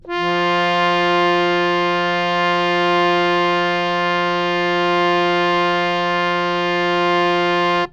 interactive-fretboard / samples / harmonium / F3.wav